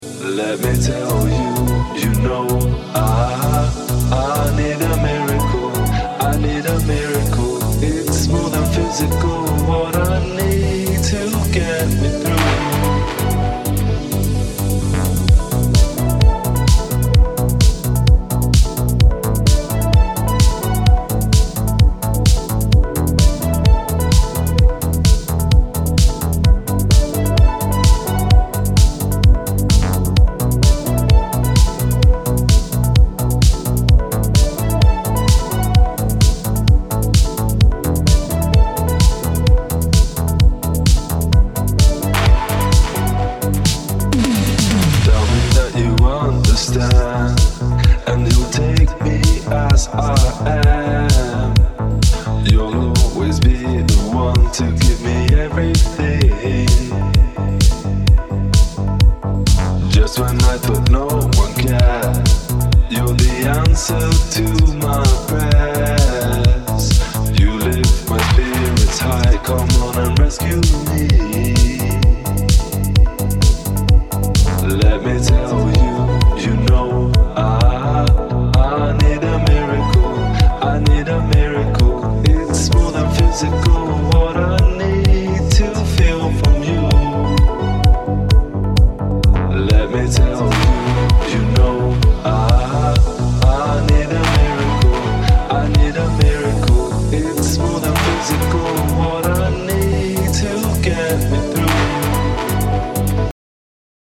Italo Version